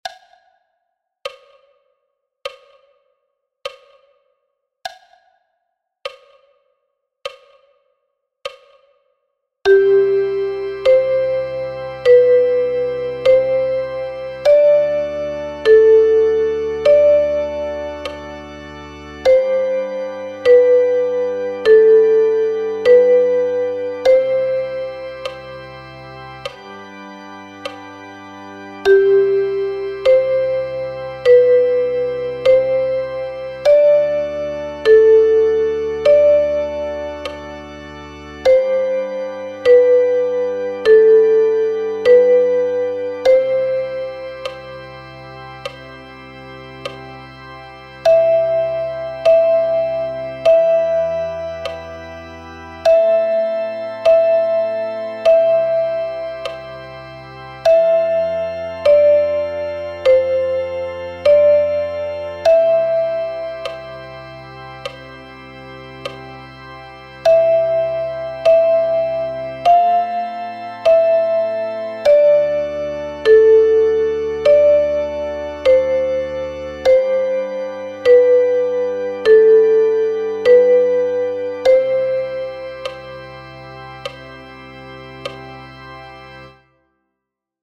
12 Evergreens – Duette für Sopran- & Altblockflöte